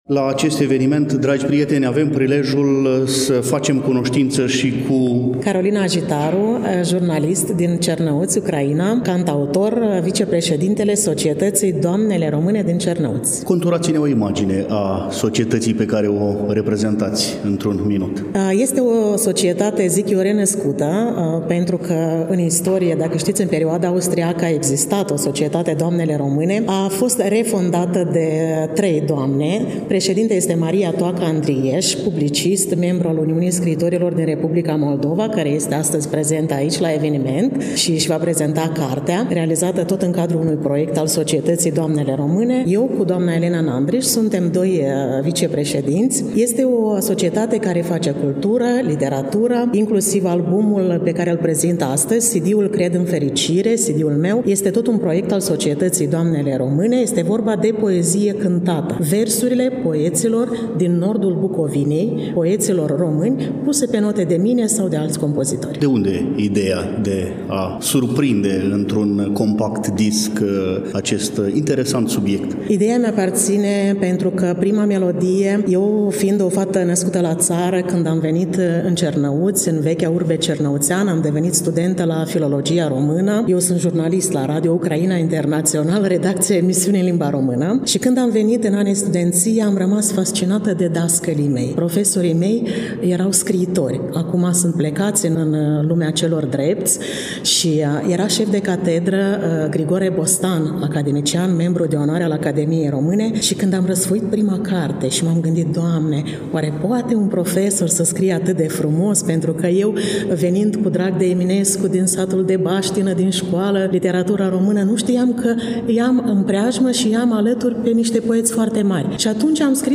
Reamintim că în ediția de astăzi a emisunii noastre relatăm de la manifestarea „Interacțiuni literare transfrontaliere”, eveniment care a avut loc, la Iași, în Sala „Ștefan Procopiu” din incinta Muzeului Științei și Tehnicii „Ștefan Procopiu”, Complexuul Muzeal Național „Moldova” Iași.
Concluzia interviului pe care-l postăm constă într-un mesaj pentru fiecare dintre noi, locuitori ai acestor ținuturi biecuvântate, mesaj care sună astfel: „păstrați ceea ce ne-a dat Dumnezeu”.